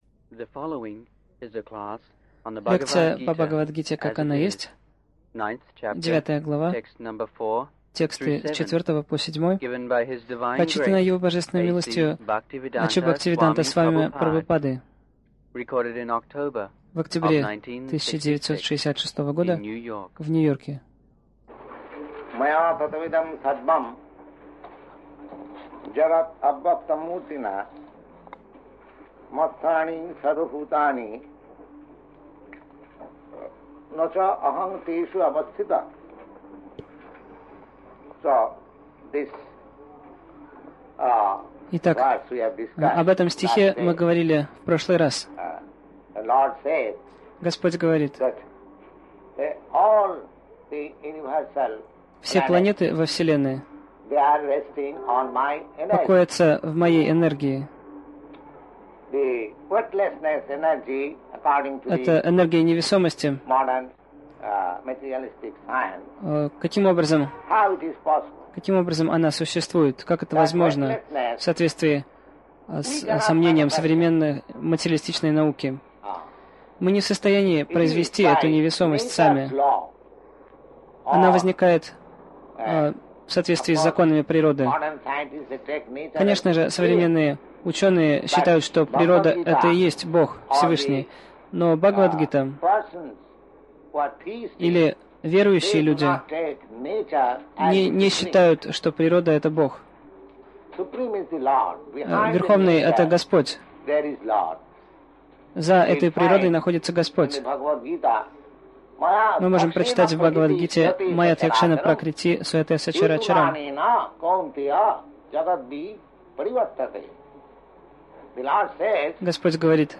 Милость Прабхупады Аудиолекции и книги 25.10.1966 Бхагавад Гита | Нью-Йорк БГ 09.04-07 Загрузка...